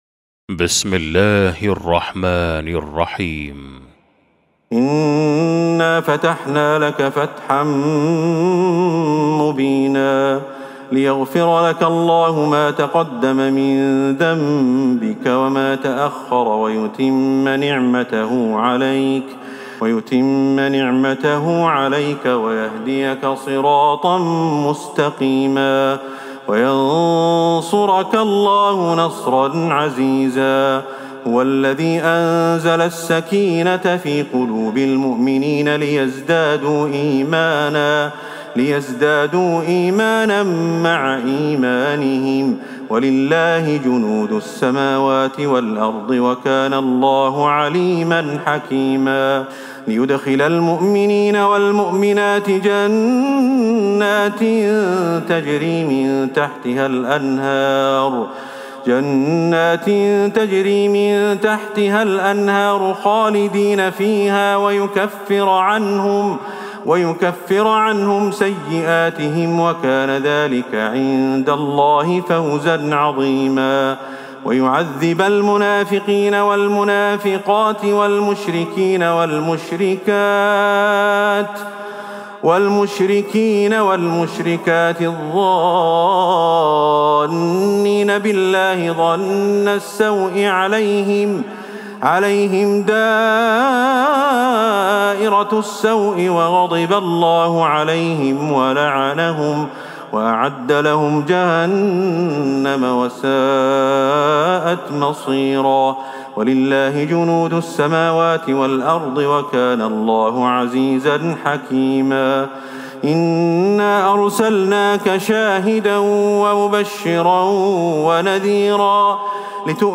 سورة الفتح Surat Al-Fath > مصحف تراويح الحرم النبوي عام 1443هـ > المصحف - تلاوات الحرمين